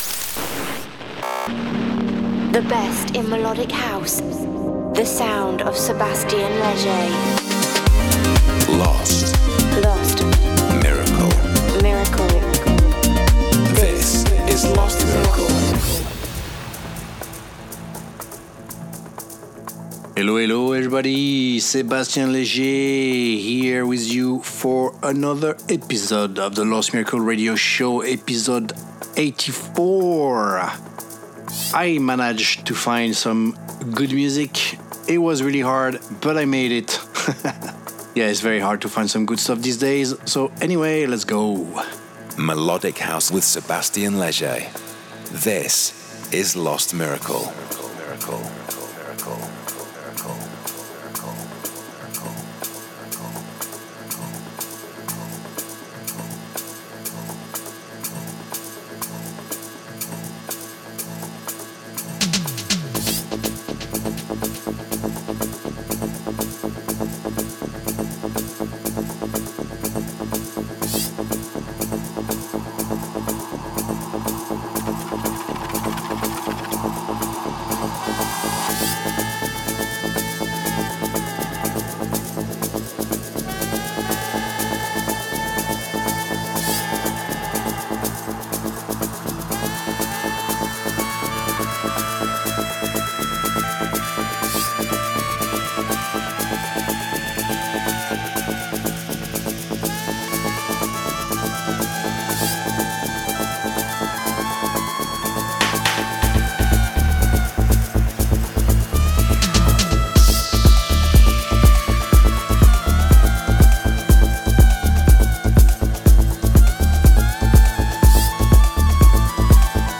monthly radio show